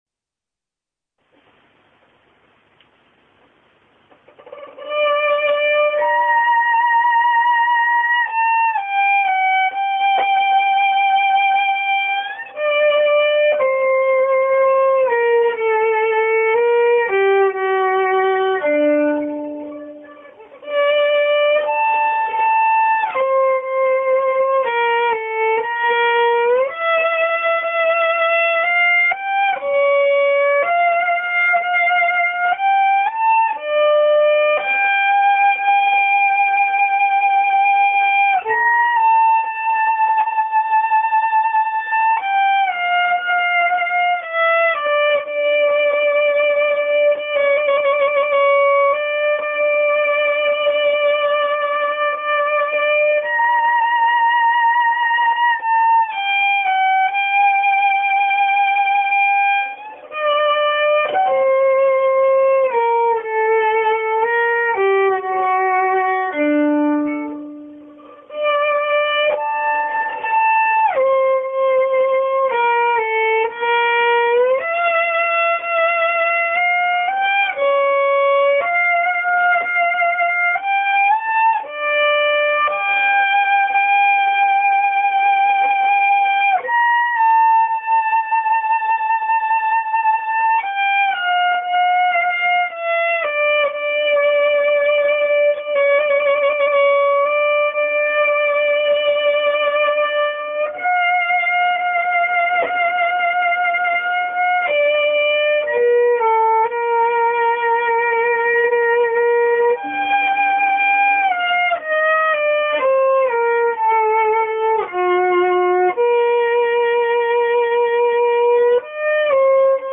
ヴァイオリン